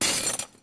terumet_break.0.ogg